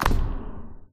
soundadd: Downmixed every sound to mono.